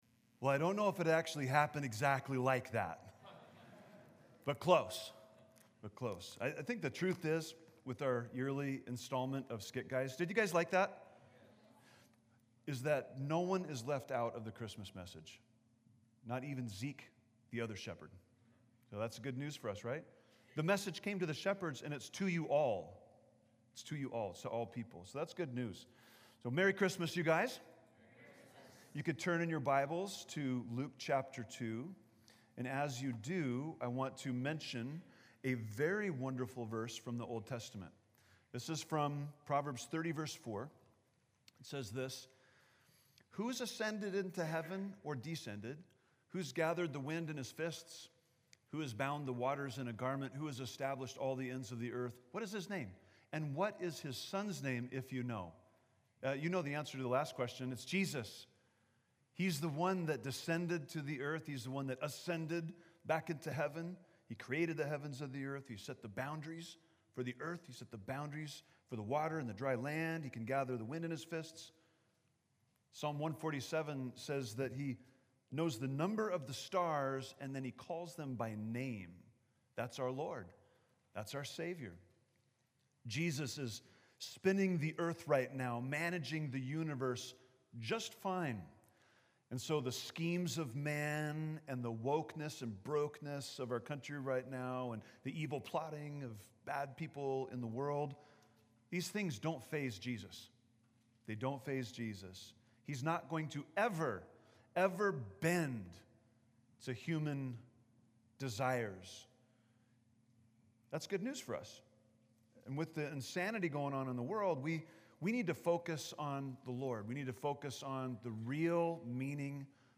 Christmas Eve 2024 Candlelight Service